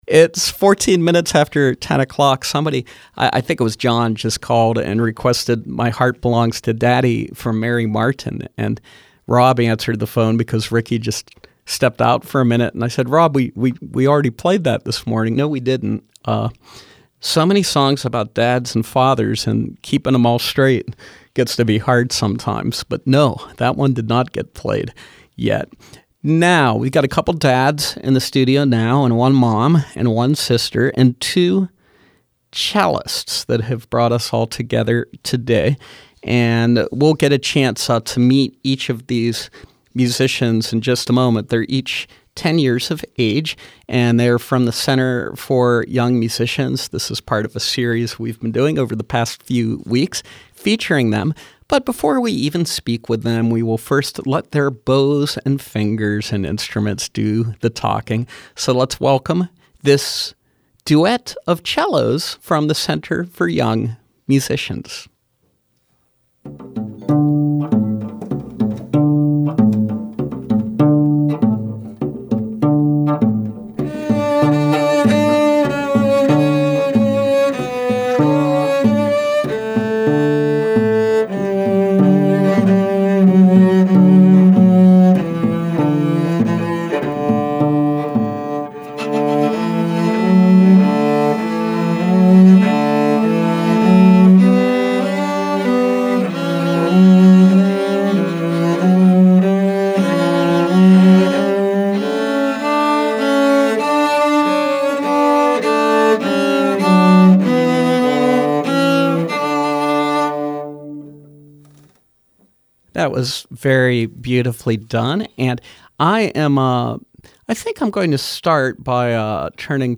Feature: Center for Young Musicians Cellists